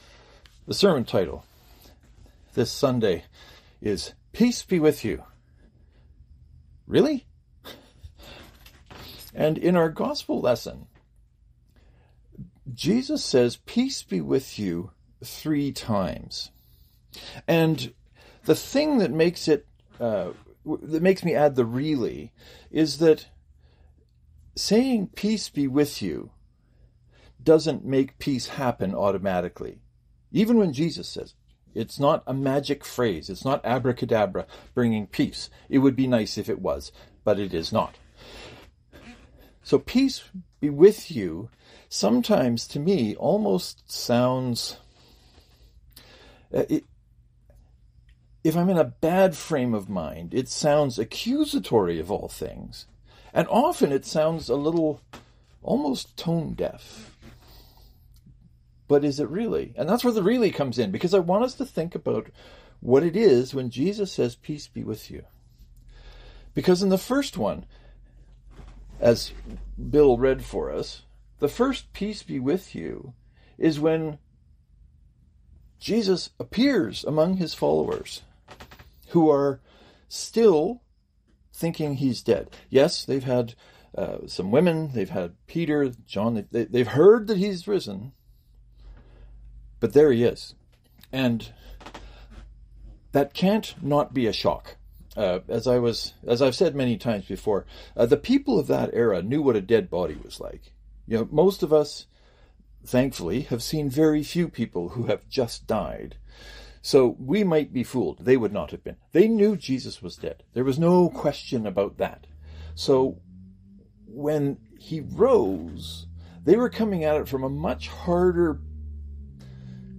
This week’s sermon is about peace being with us.